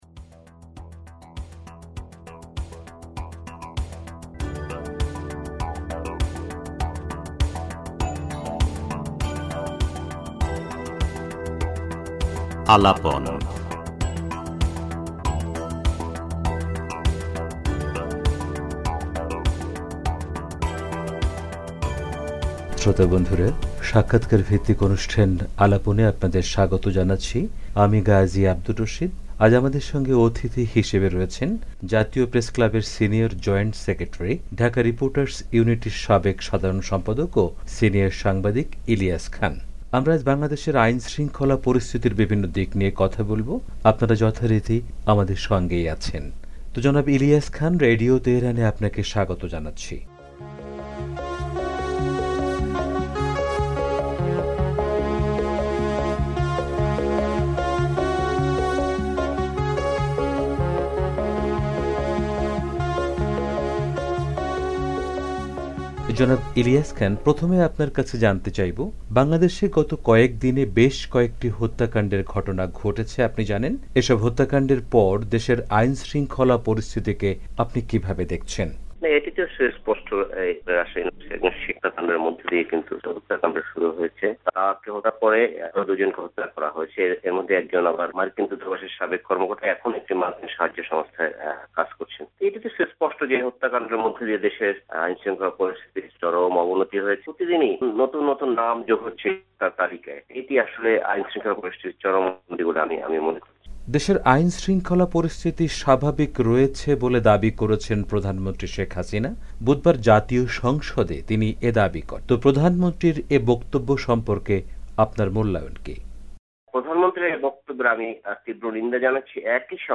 পুরো সাক্ষাৎকারটি উপস্থাপন করা হলো।